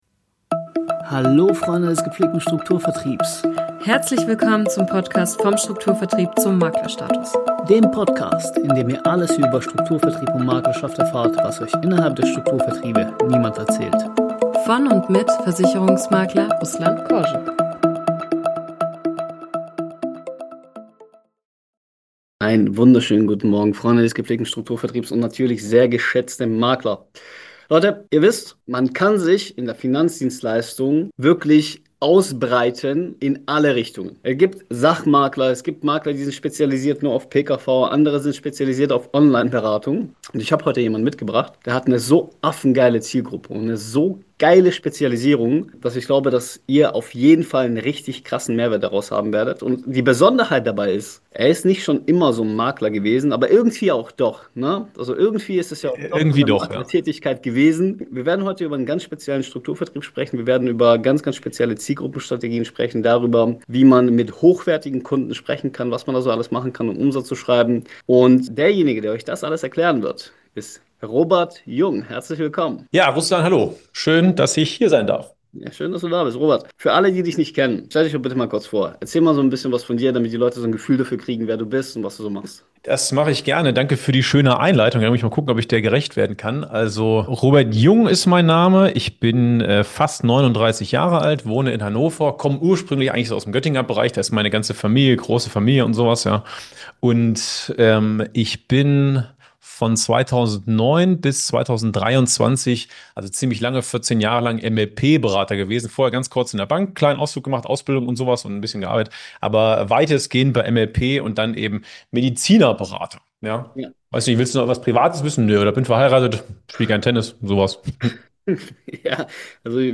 Ein ehrliches Gespräch über Mut, Veränderung und die Frage, was Kunden wirklich brauchen.